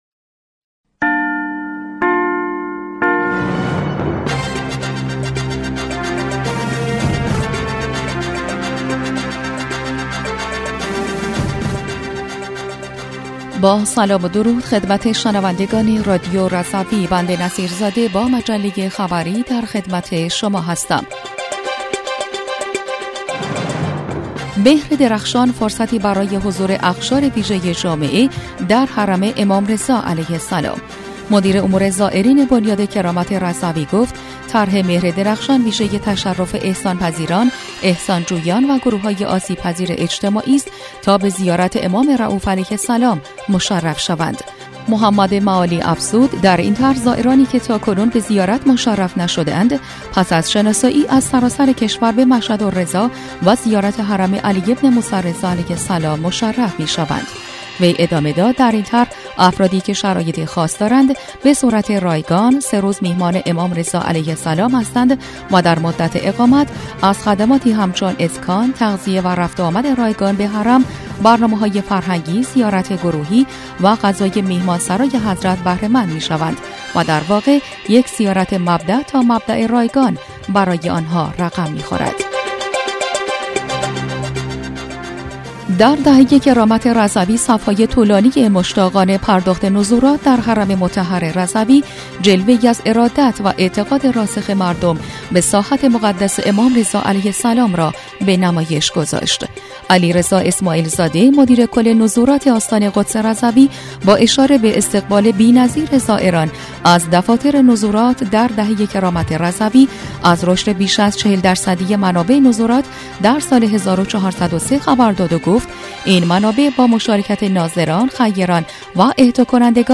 بسته خبری ۲۱ اردیبهشت ماه/